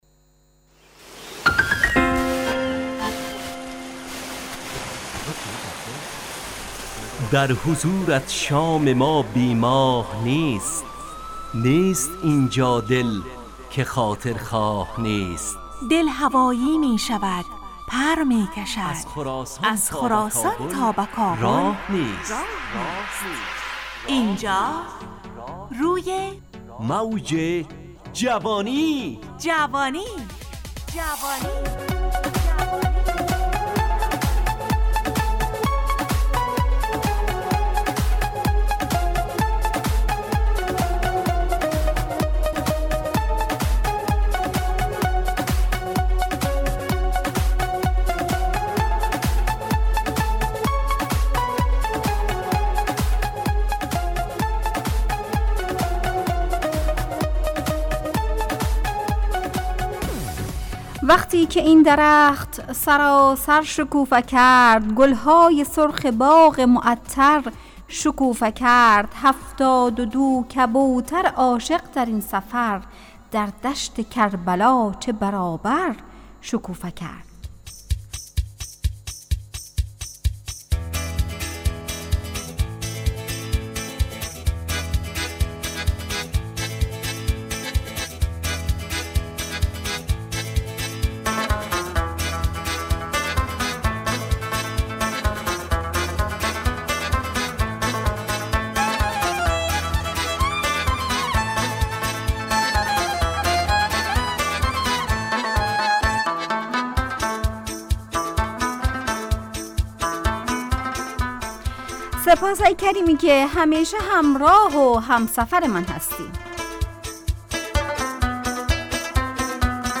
روی موج جوانی، برنامه شادو عصرانه رادیودری. از شنبه تا پنجشنبه ازساعت 17 الی 17:55 طرح موضوعات روز، وآگاهی دهی برای جوانان، و.....بخشهای روزانه جوان پسند....
همراه با ترانه و موسیقی .